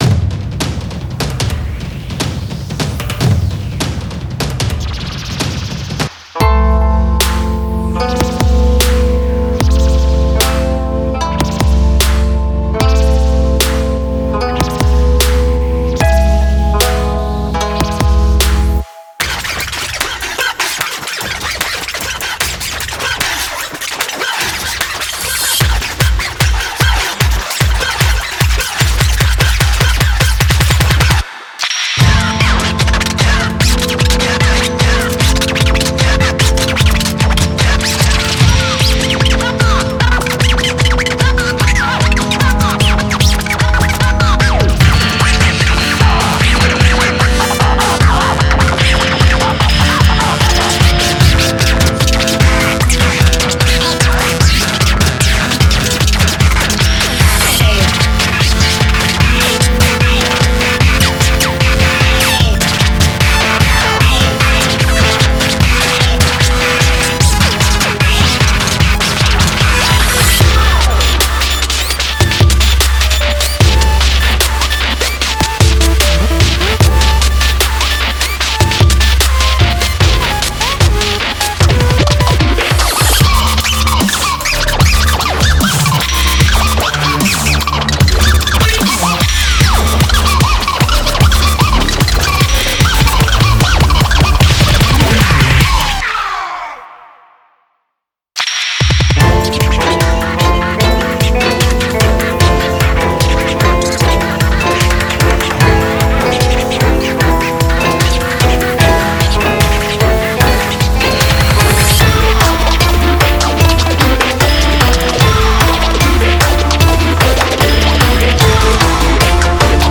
BPM75-150
Audio QualityPerfect (High Quality)